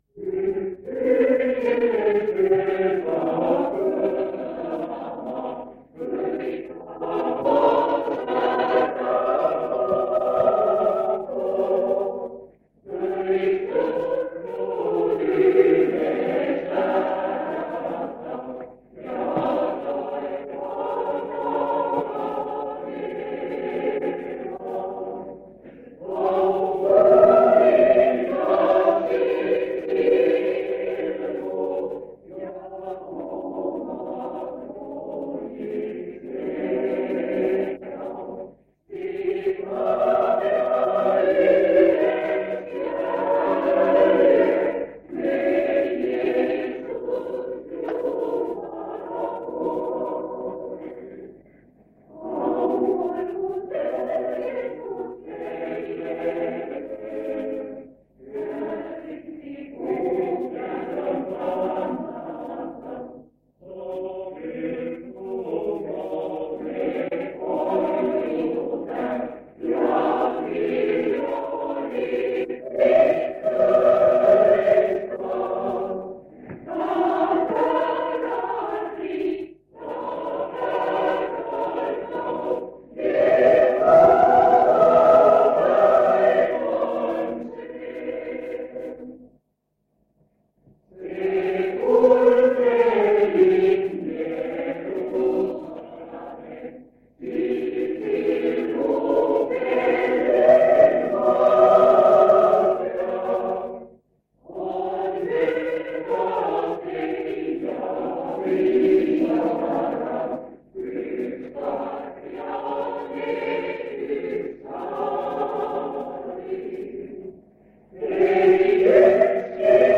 Jutlused
Koosolek vanalt lintmaki lindilt 1977 aastast.